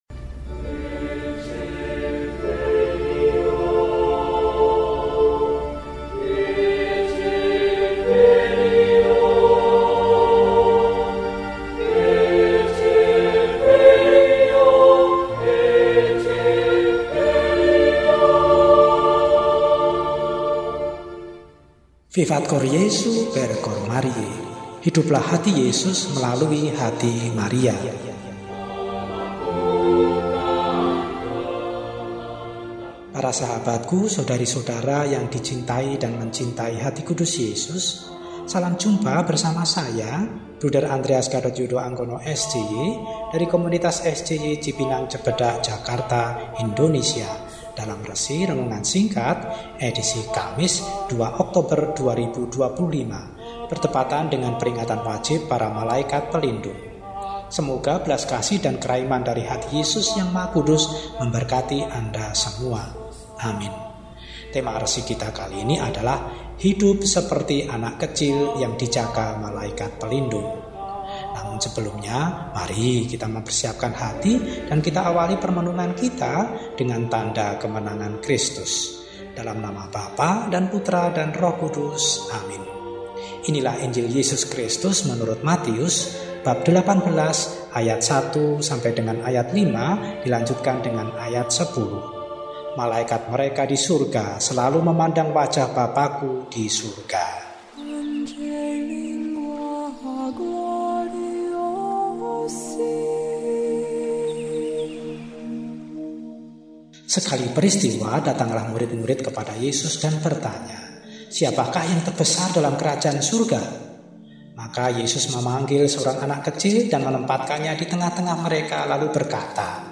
Kamis, 02 Oktober 2025 – Peringatan Wajib Para Malaikat Pelindung – RESI (Renungan Singkat) DEHONIAN